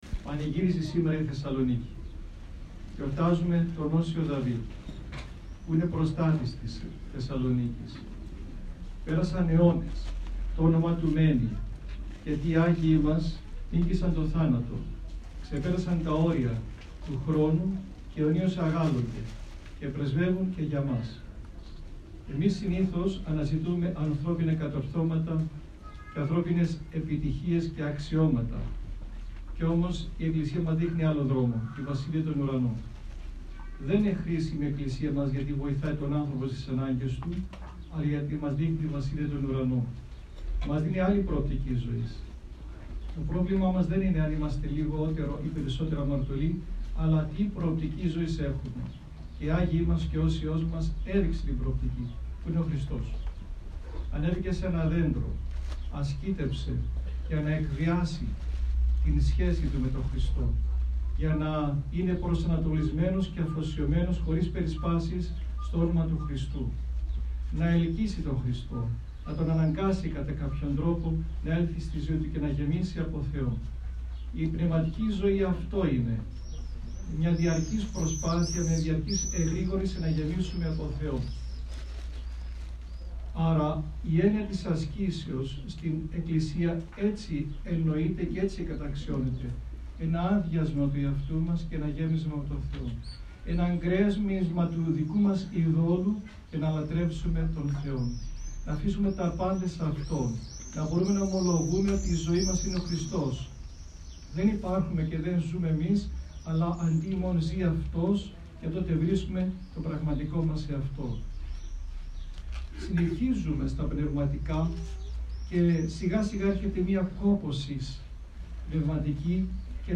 Την Ιερά μνήμη του οσίου Δαυίδ του εν Θεσσαλονίκη τίμησε σήμερα στην Άνω Πόλη ο ευσεβής λαός της Θεσσαλονίκης με τον πανηγυρικό εσπερινό του Αγίου στο ομώνυμο καθολικό της ιεράς Μονής Λατόμου.